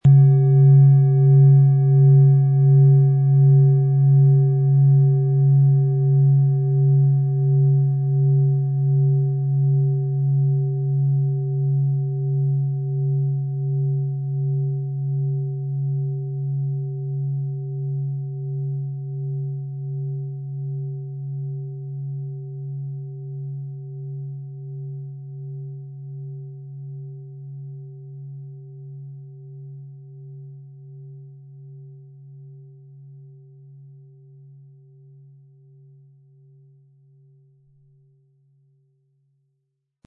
Planetenklangschale Tageston Frequenz: 194,18 Hz
Gratisversand In Handarbeit hergestellt Material: Bronze Zu diesen Klangschalen wird Ihnen selbstverständlich der passende Klöppel geliefert.